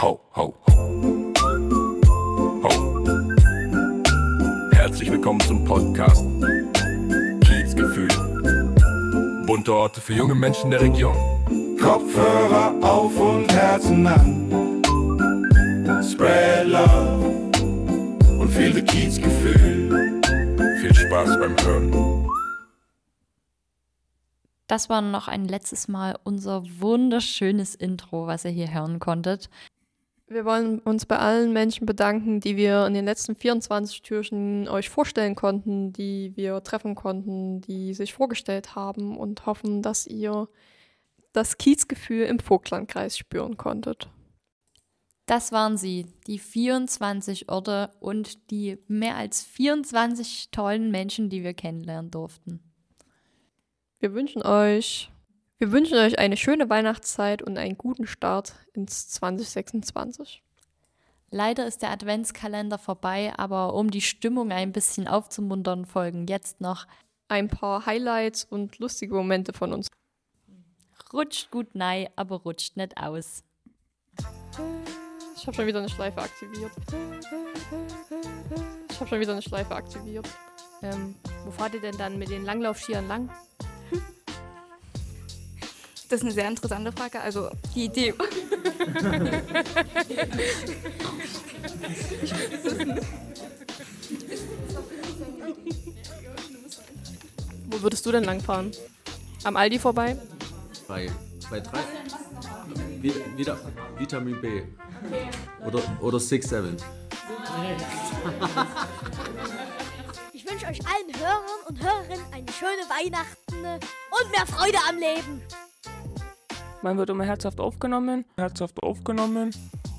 gibt’s natürlich: Outtakes, Lacher & herrliche Versprecher –